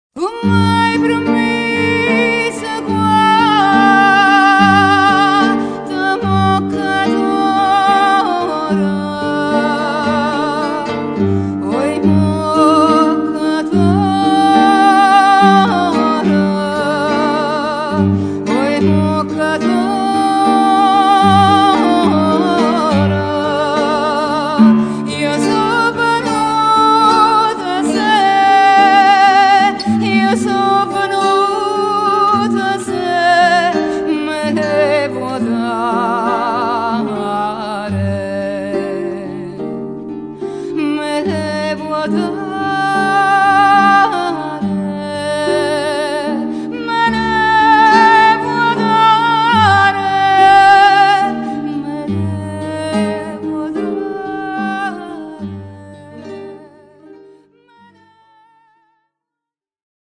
chitarra, chitarra battente, mandolino, mandola, percussioni